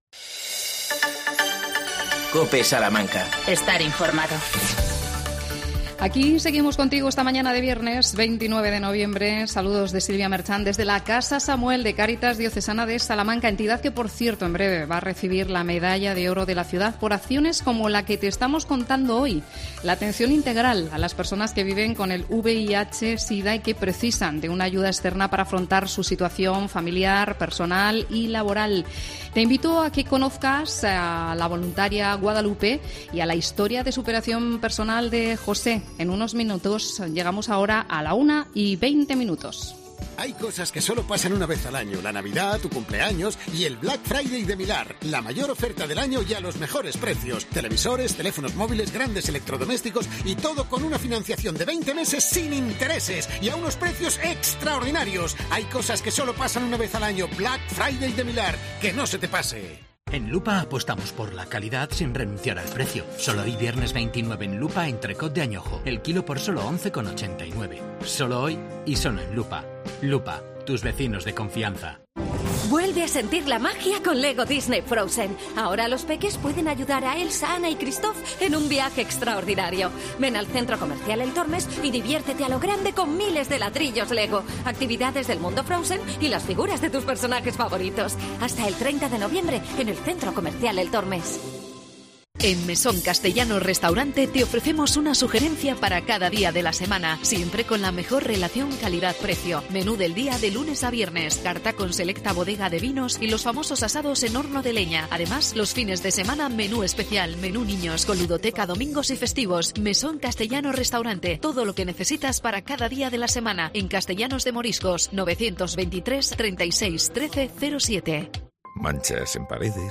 AUDIO: Programa especial desde la casa de acogida para enfermos de VIH "Casa Samuel".